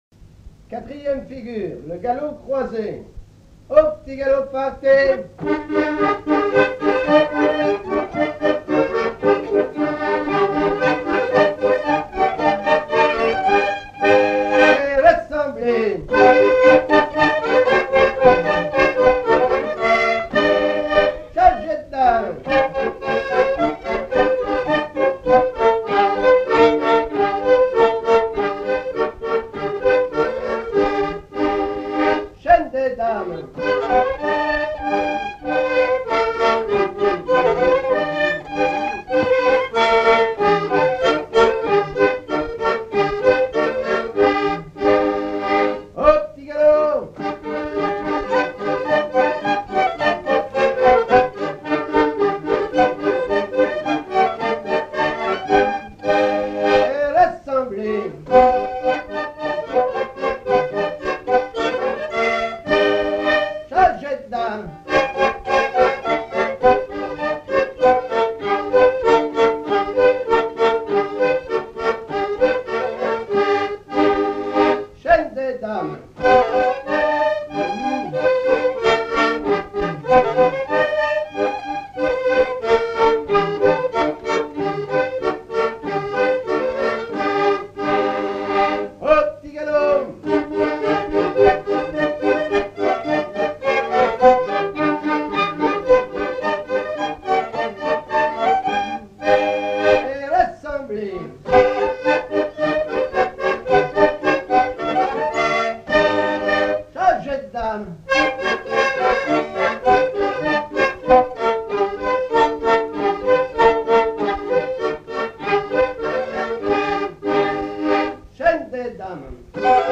Rochetrejoux
danse : quadrille : petit galop
Pièce musicale inédite